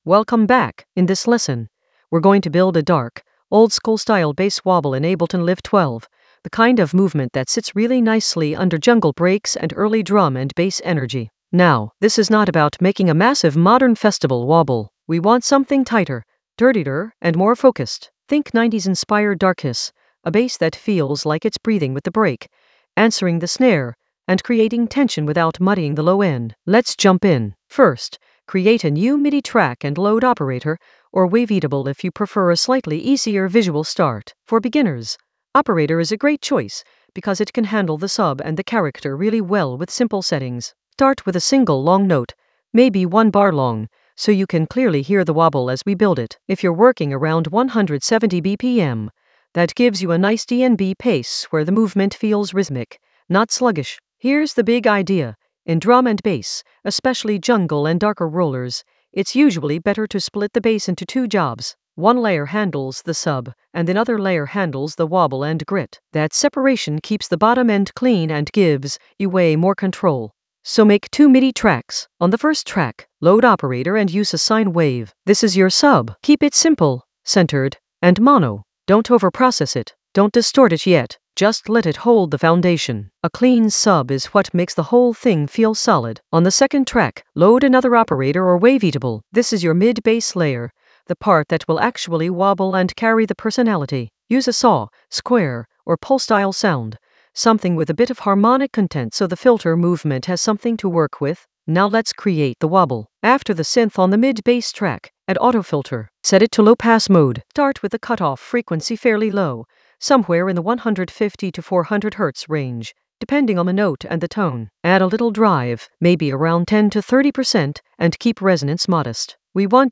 An AI-generated beginner Ableton lesson focused on Pull a bass wobble for 90s-inspired darkness in Ableton Live 12 for jungle oldskool DnB vibes in the FX area of drum and bass production.
Narrated lesson audio
The voice track includes the tutorial plus extra teacher commentary.